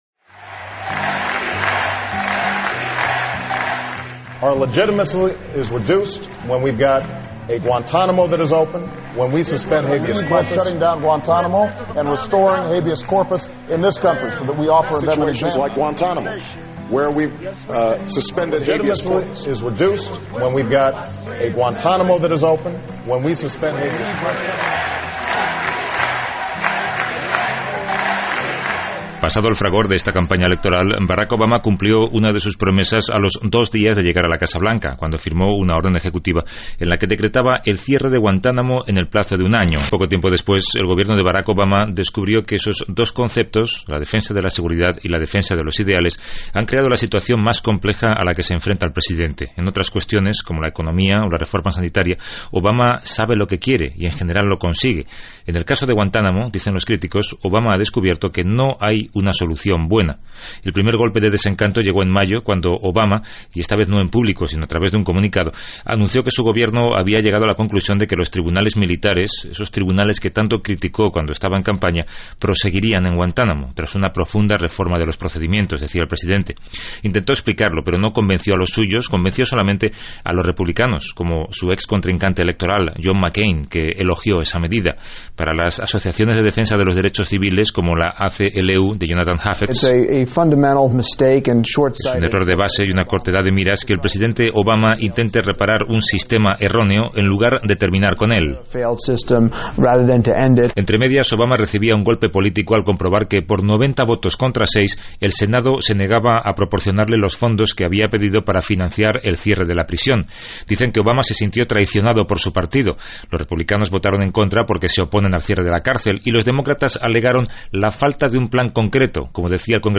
Reportatge sobre la presó de Guantánamo que tenen els EE.UU. a Cuba i la decisió de l'administració del president Barack Obama de tancar-la, sense haver-ho aconseguit
Informatiu